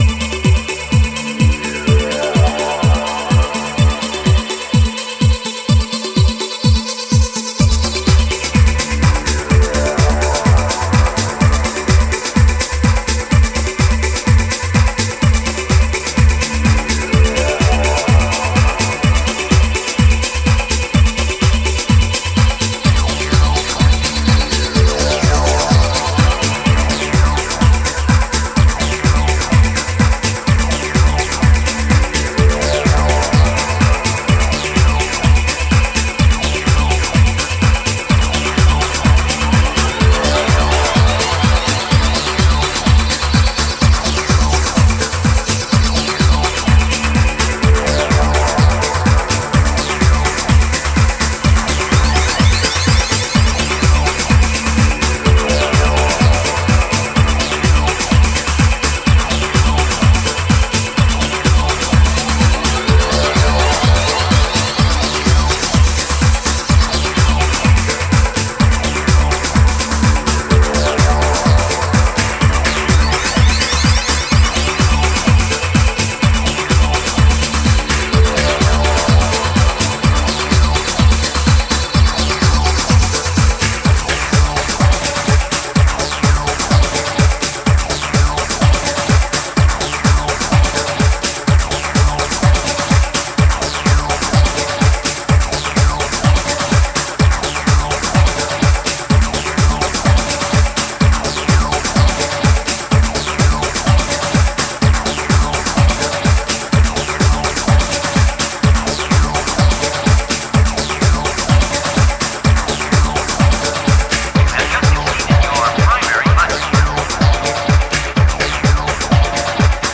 a little more tranced out and cosmic